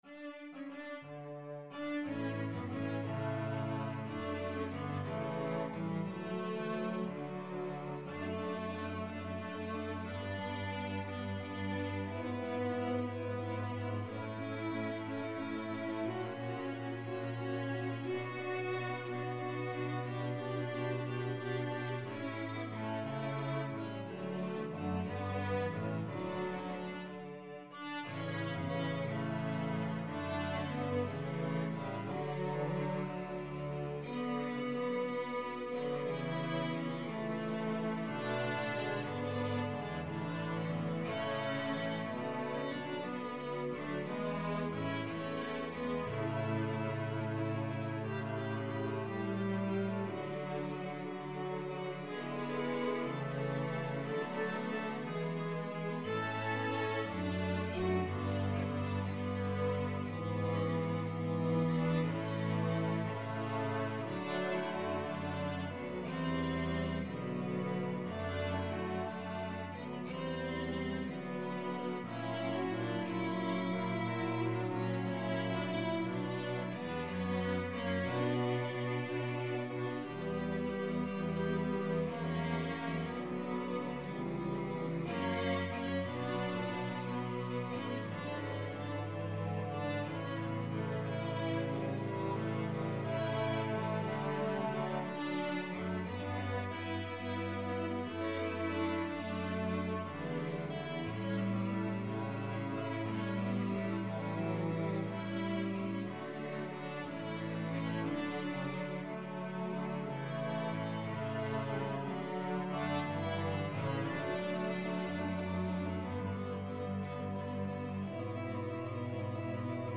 Cello 1Cello 2Cello 3Cello 4
12/8 (View more 12/8 Music)
Slow . = c. 60
Classical (View more Classical Cello Quartet Music)